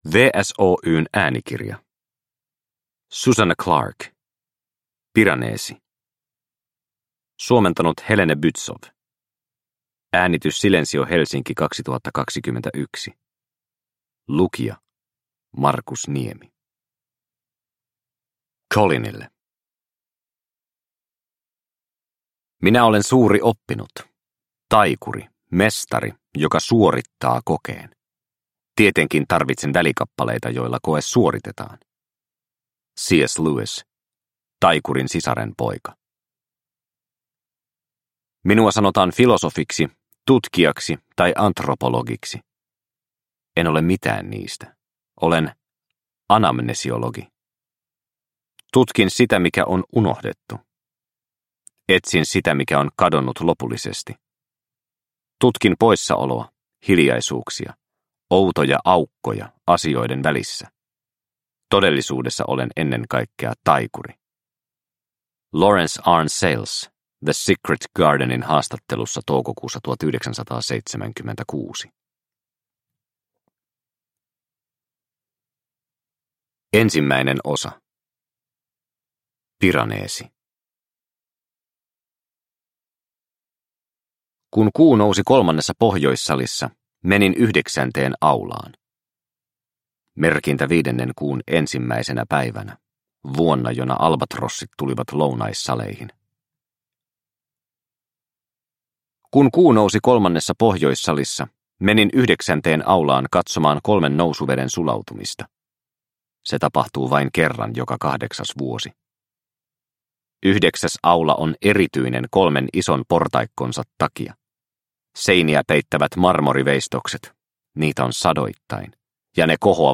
Piranesi – Ljudbok – Laddas ner